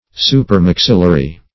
Supermaxillary \Su`per*max"il*la*ry\, a.
supermaxillary.mp3